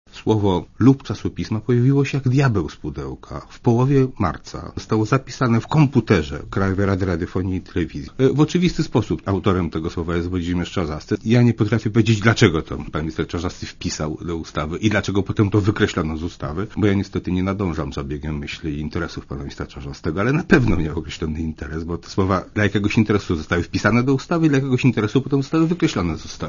Posłuchaj wypowiedzi Tomasz Nałęcza